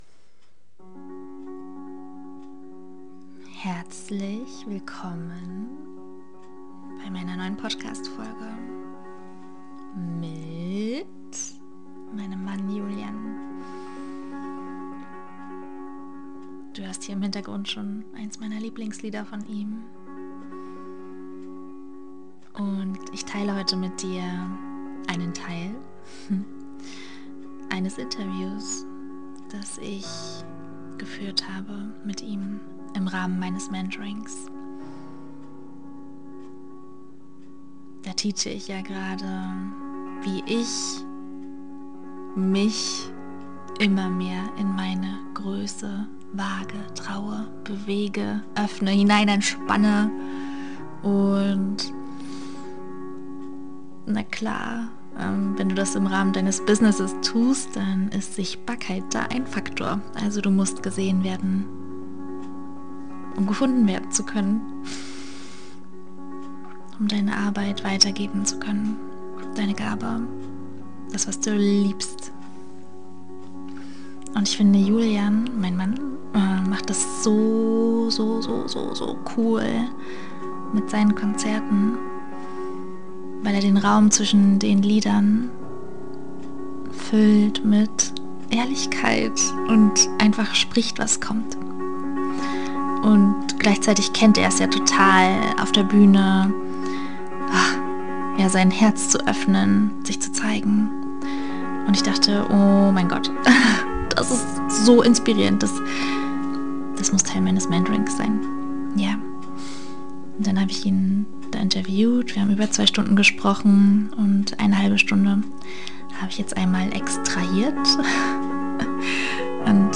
Einen Auszug aus diesem Interview teile ich mit dir in dieser Folge. Was passiert, wenn Menschen während des Konzerts gehen? Wie authentisch „dürfen“ wir wirklich sein, wenn 300 oder 1000 Menschen im Raum sind?